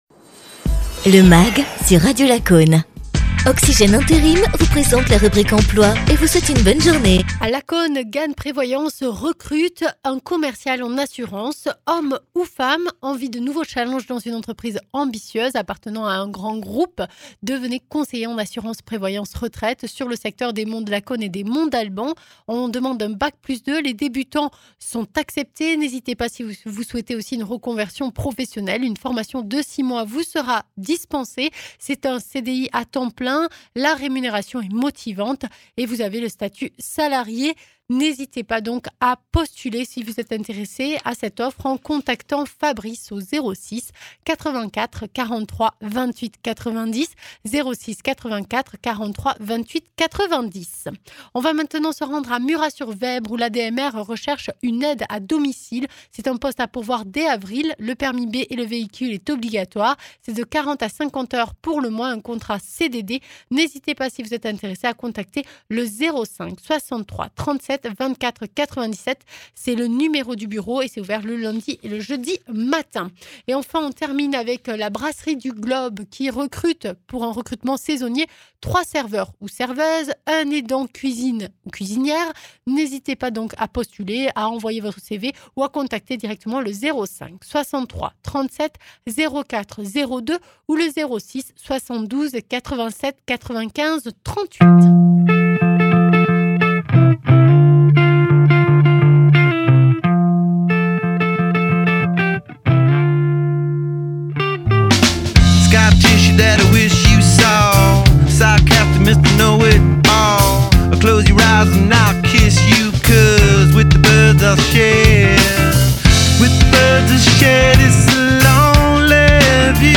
Invité(s) : Daniel Vidal, maire de Murat-sur-Vèbre (Tarn)